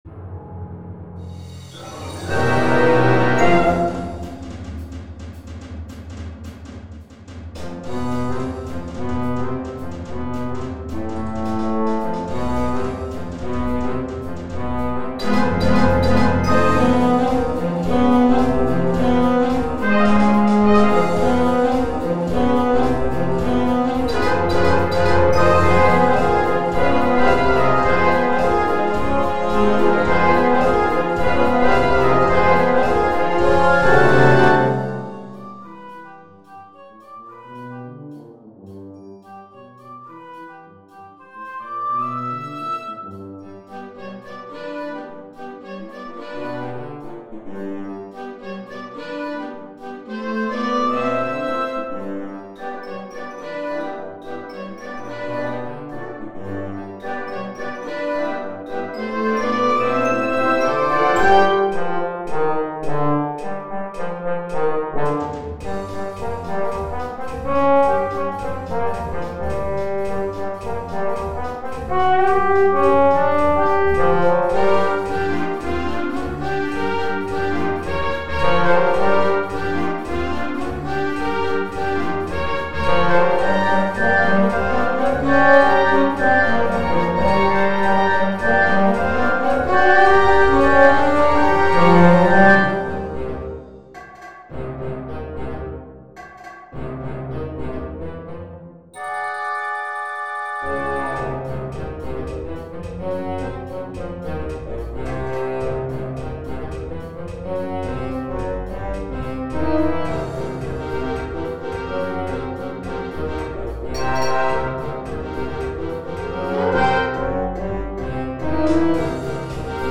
Gattung: Konzertante Unterhaltungsmusik für Blasorchester
4:30 Minuten Besetzung: Blasorchester PDF